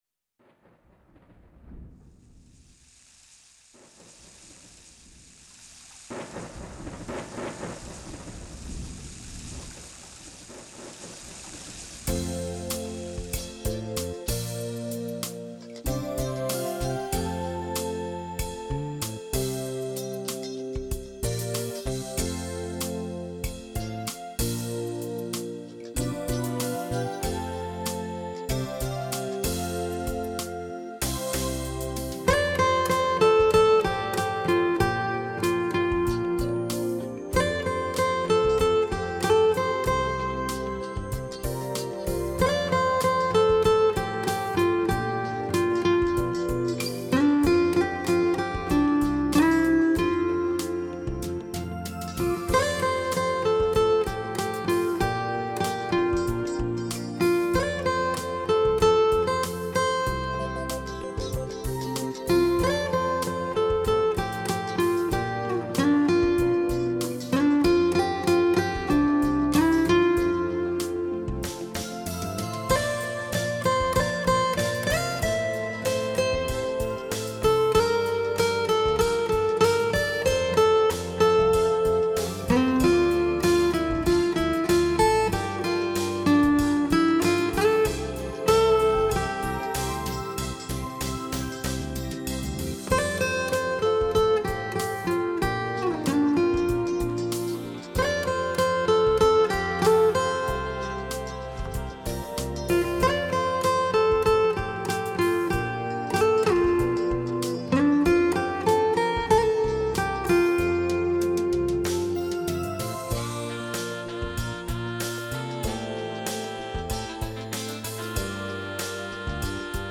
24Bit/96Khz的多轨数码录音。
保证了音色的清晰度及更宽的动熊范围。
6个声道的数码输出，